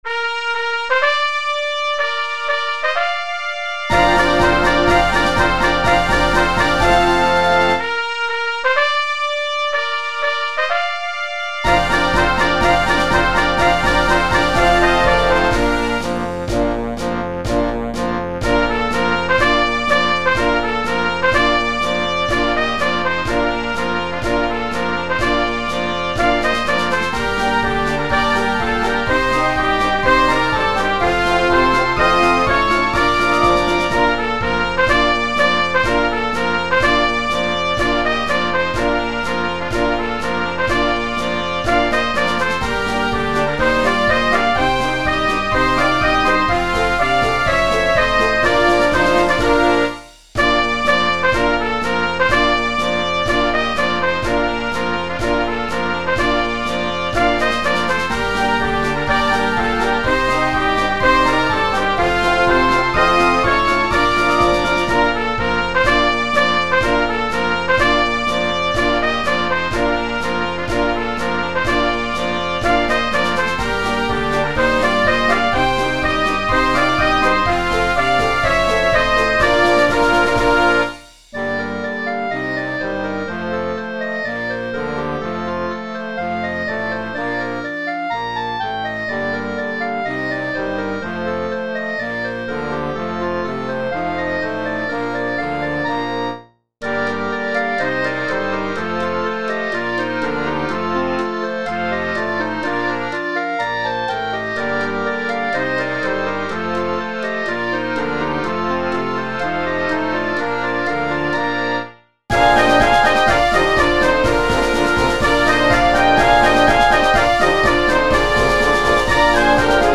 Marcia per Banda Brillante Marcia militare